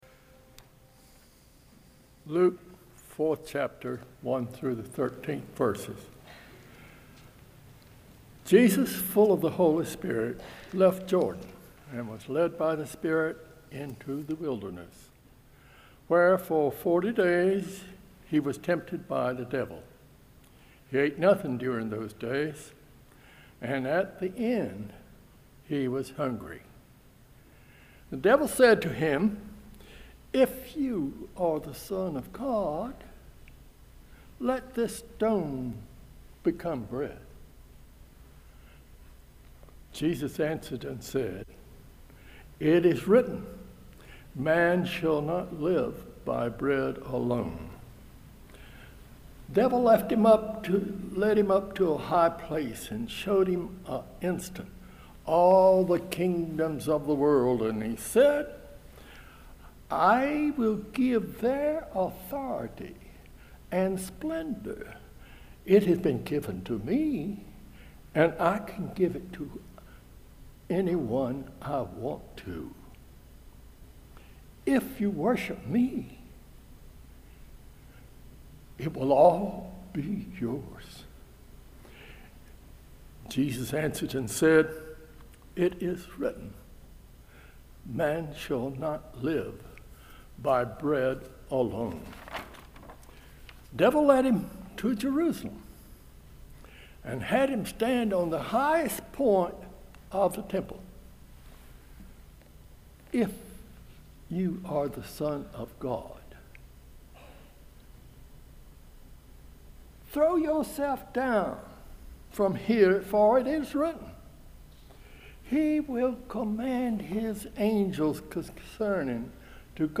We wrestle day after day with issues pertaining to the presence of evil in the world, human sexuality, immigration, and so on. This Lent, our sermon series will focus on taking a look at these pressing issues in light of the cross of Jesus Christ.